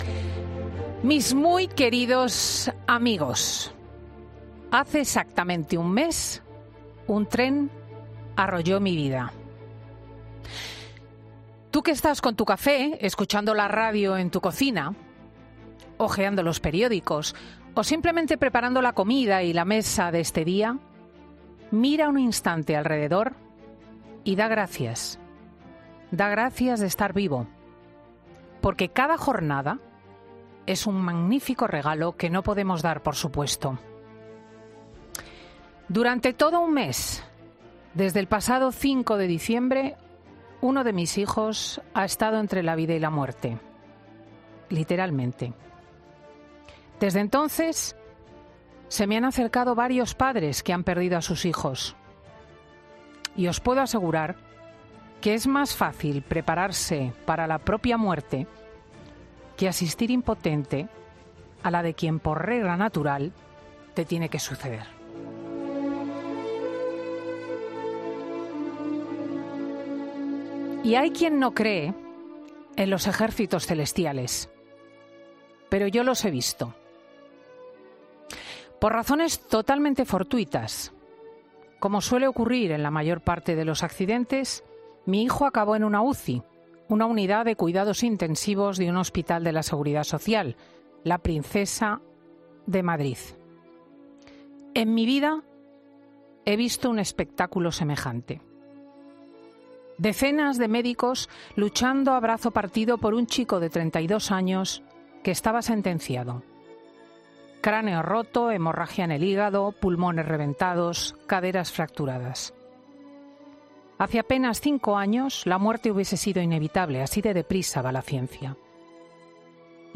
Monólogo de Cristina López Schlichting
La directora de 'Fin de Semana', Cristina López Schlichting, habla en su monólogo sobre el gran regalo que ha recibido por Reyes